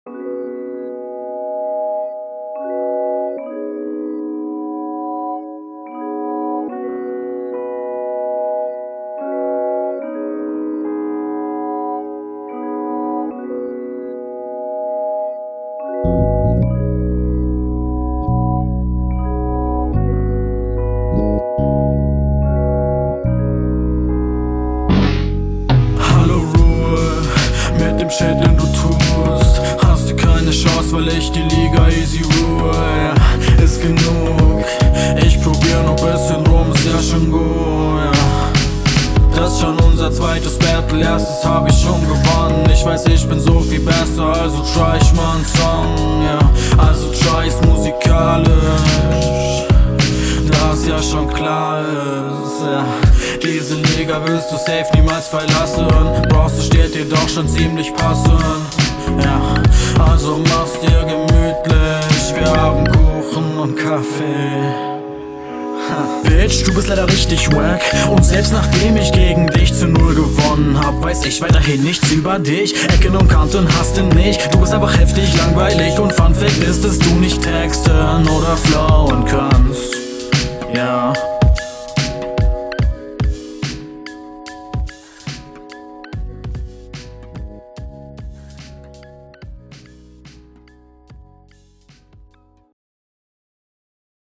Flowtechnisch eine sehr coole Runde auch super Musikalisch, ich finde nur das die Soundqualität da …
Der Stimmeinsatz ist die meiste Zeit chillig, passend zum Beat.